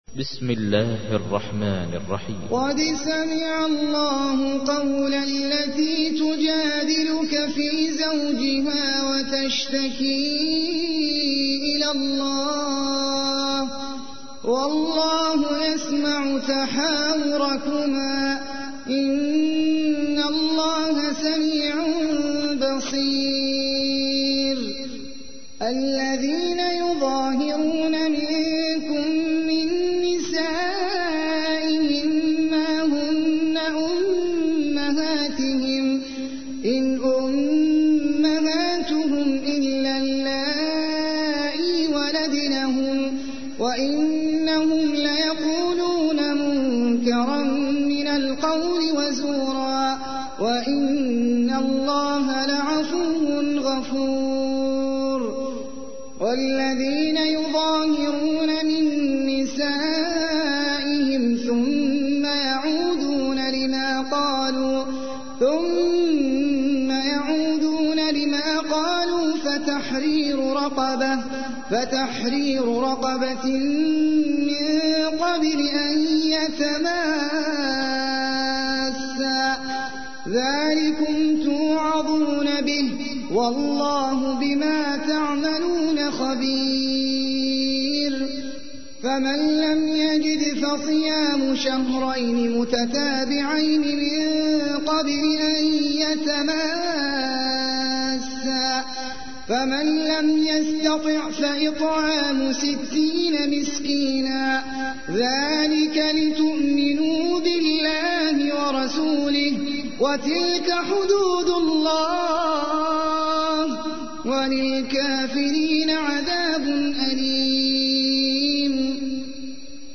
تحميل : 58. سورة المجادلة / القارئ احمد العجمي / القرآن الكريم / موقع يا حسين